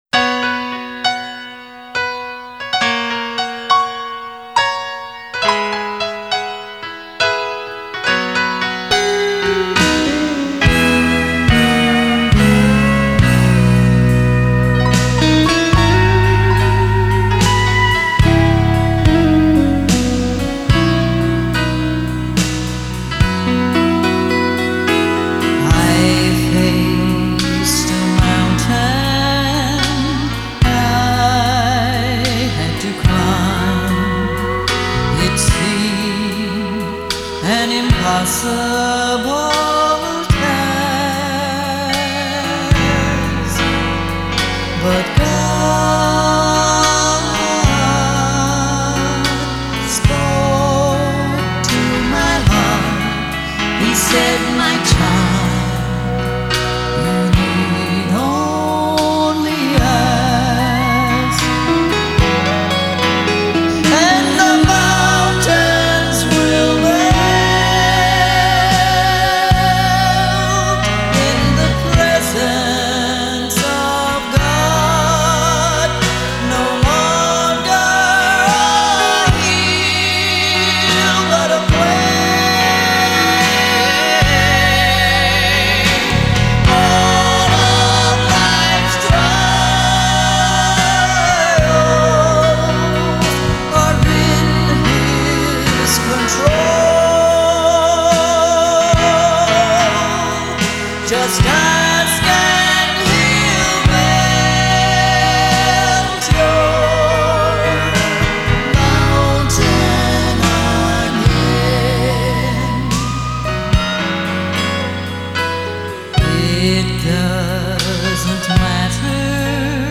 was an American singer-songwriter of Southern Gospel music.